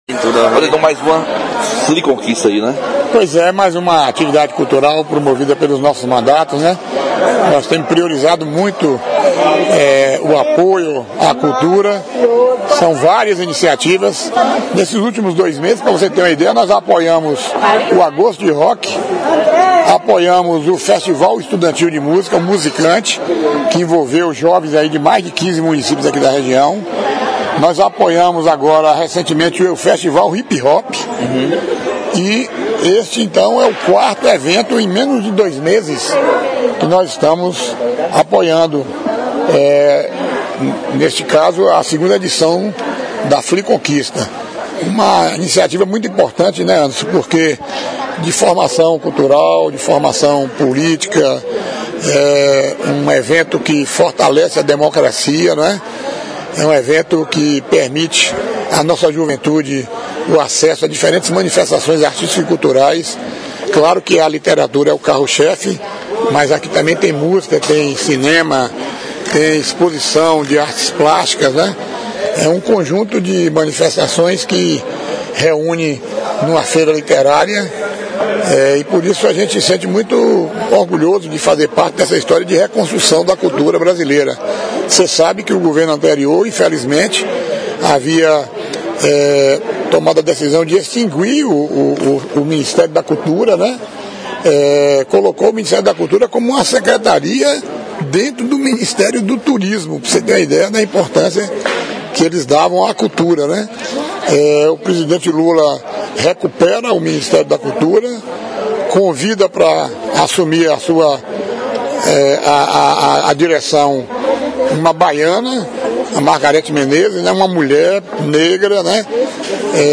BLOG Entrevista | Waldenor acompanha processo no TSE que pode mudar os rumos políticos em Vitória da Conquista